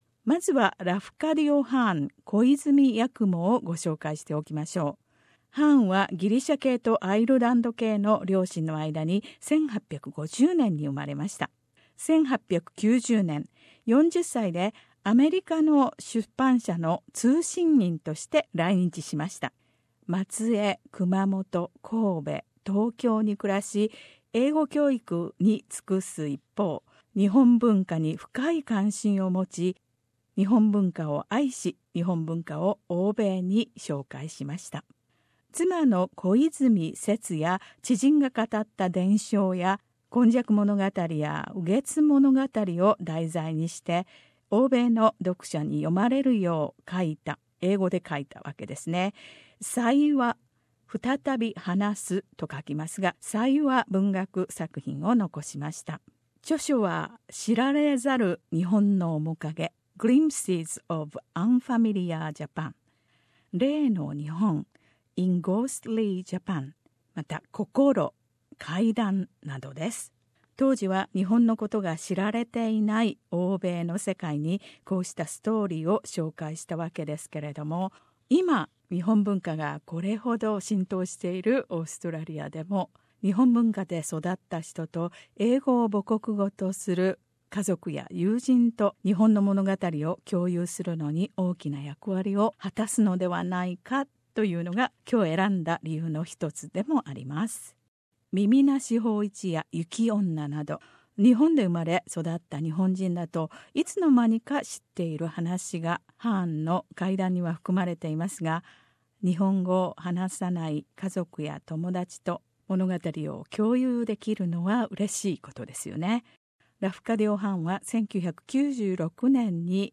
朗読の会「声」、八雲を読む
シドニーのグループ「声」の朗読で、ラフカディオ・ハーン（小泉八雲）の「怪談」から２つのストーリーを耳で味わってください。